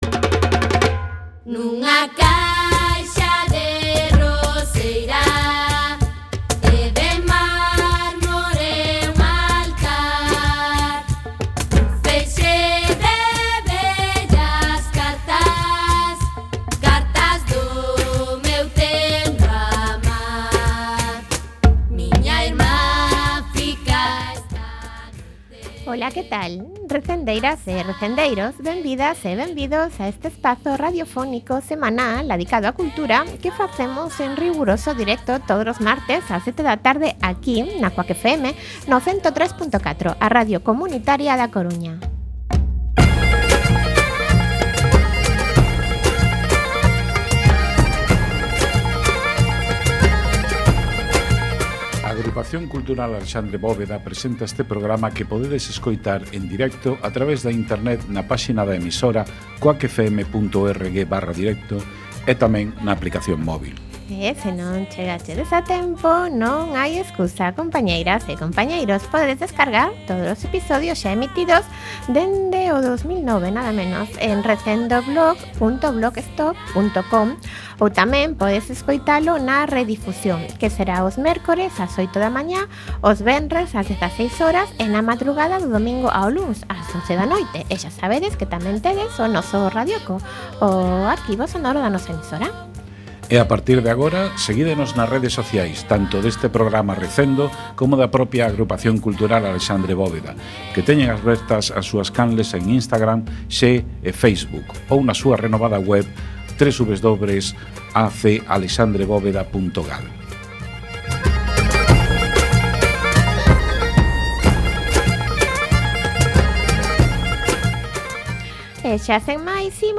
16x31 Entrevista